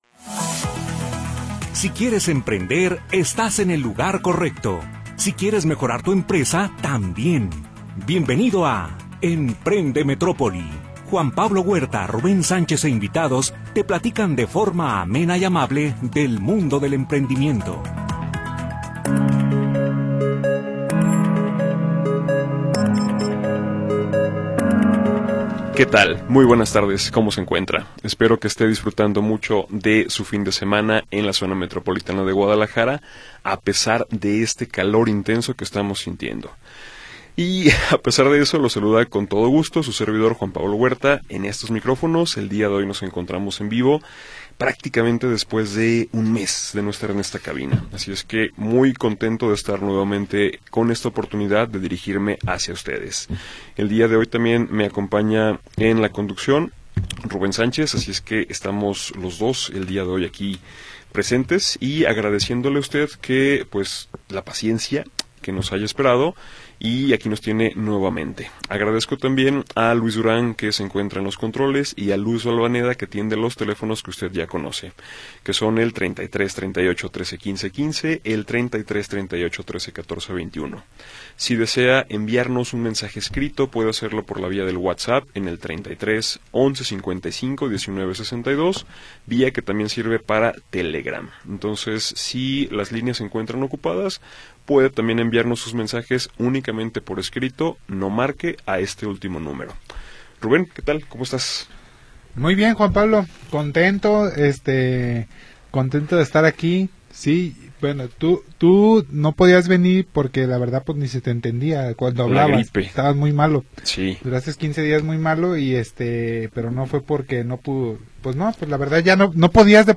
Programa transmitido el 17 de Mayo de 2025.